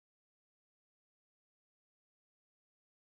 It's silent.